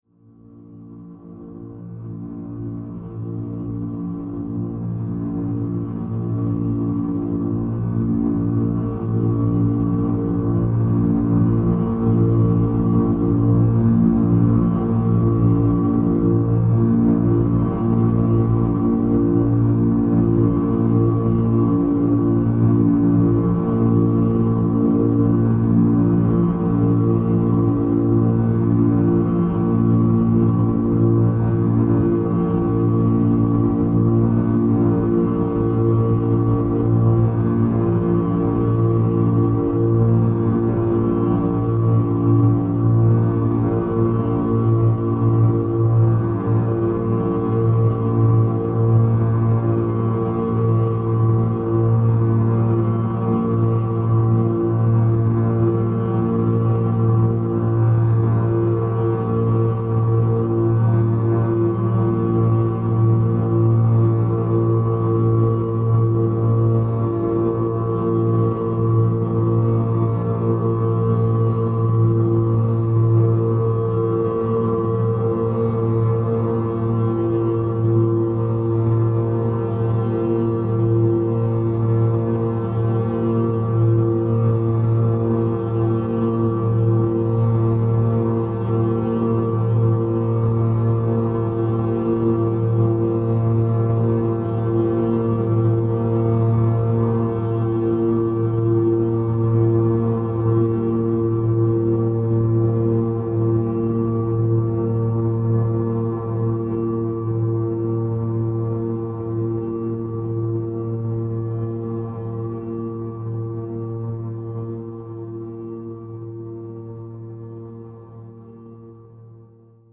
21-minute drone odyssey on acoustic guitar